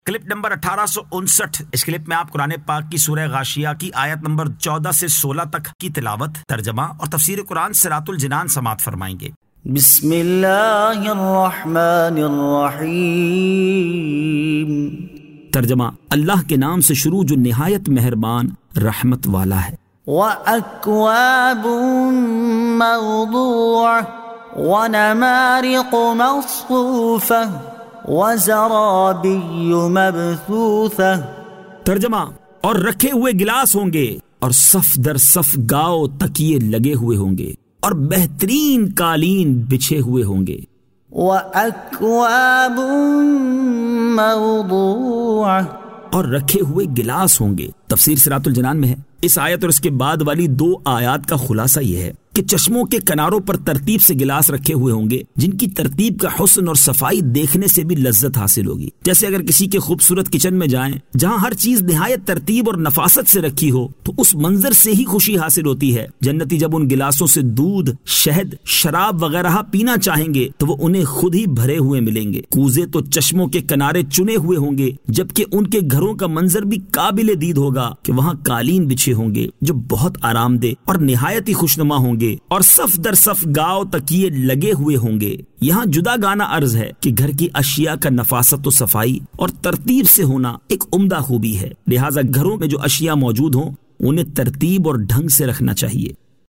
Surah Al-Ghashiyah 14 To 16 Tilawat , Tarjama , Tafseer